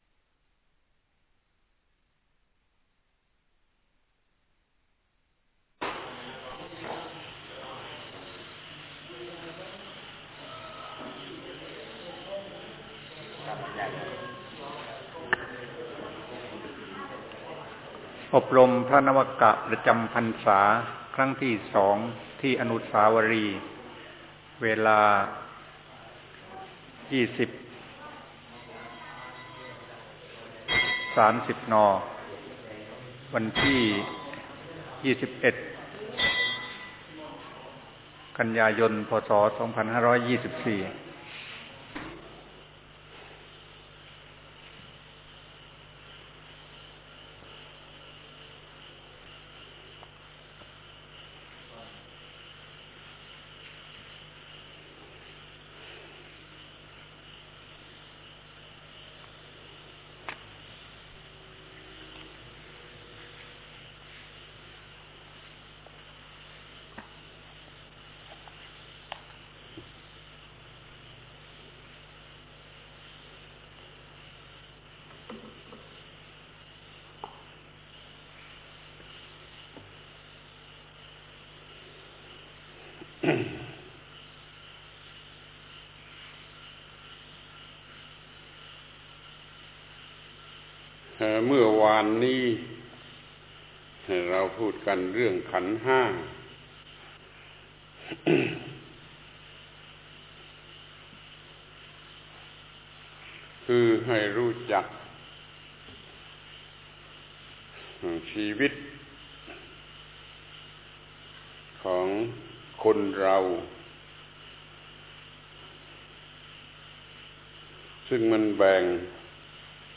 อบรมพระนวกะในพรรษา ปี 2524 ครั้ง 2 การใช้สติสัมปชัญญะเมื่อเกิดผัสสะ (อายตนะ 6)